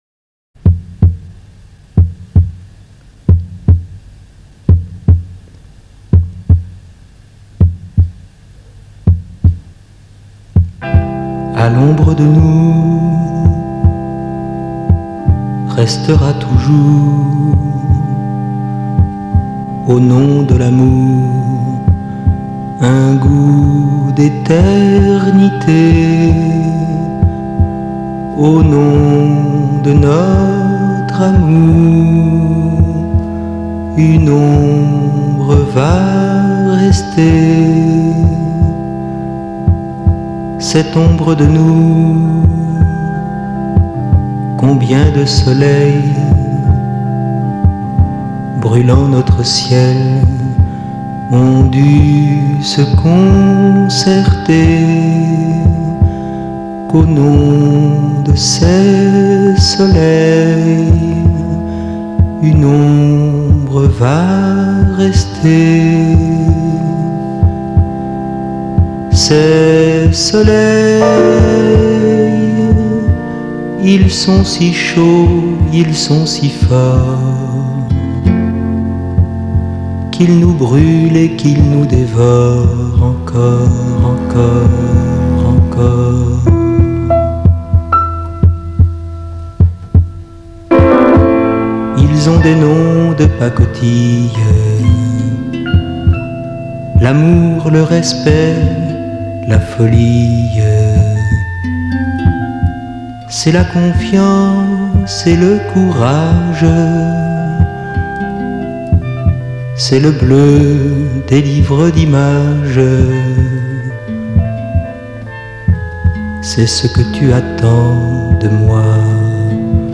(chant)